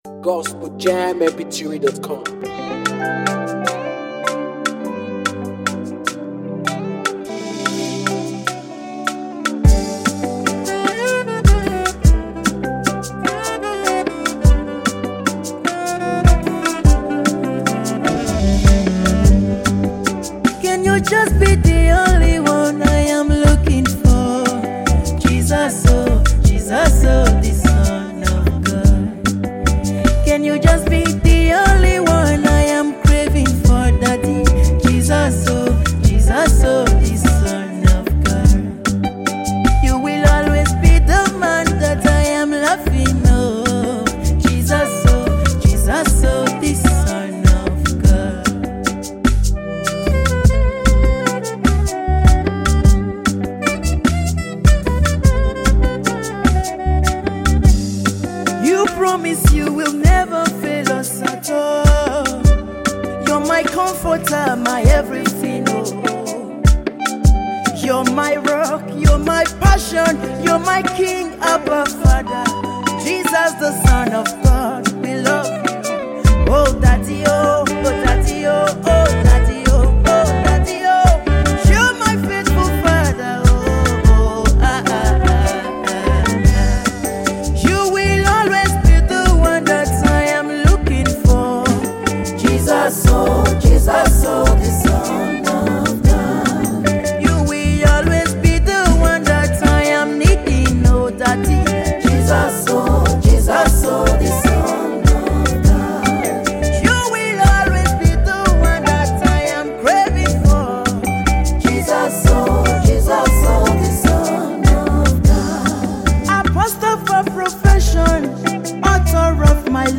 powerful gospel song